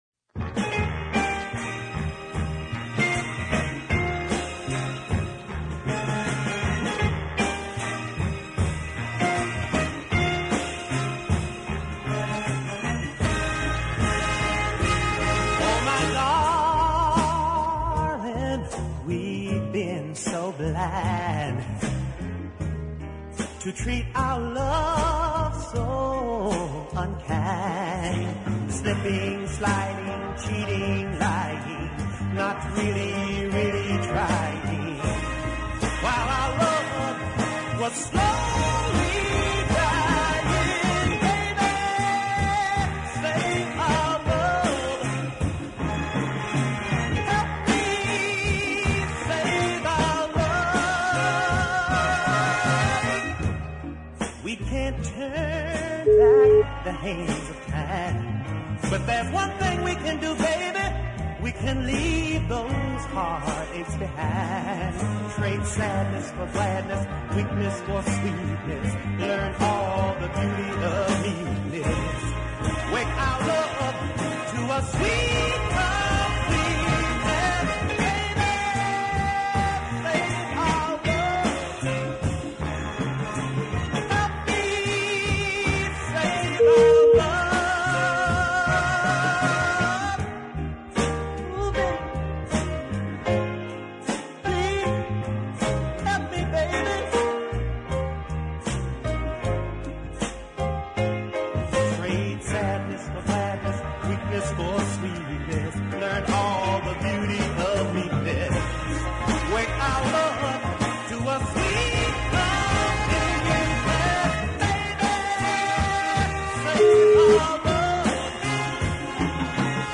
tuneful beat ballad